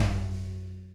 Tom Shard 02.wav